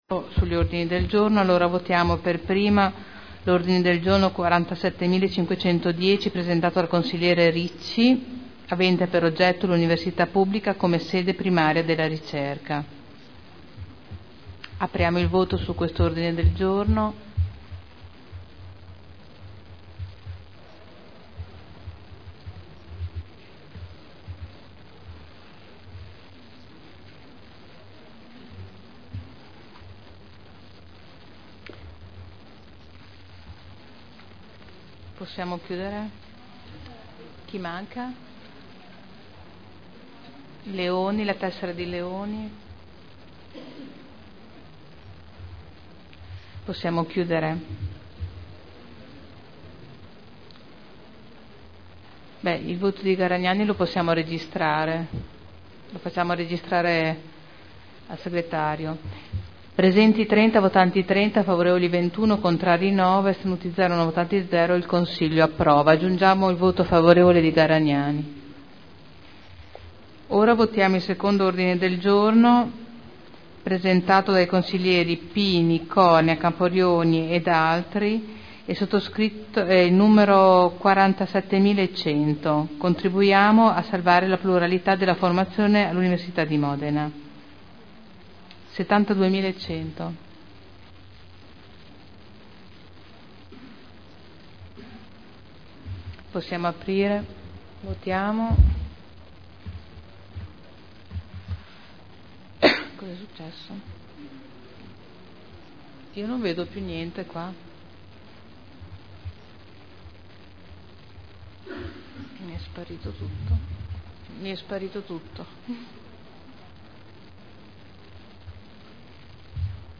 Presidente — Sito Audio Consiglio Comunale
Seduta del 18/10/2010.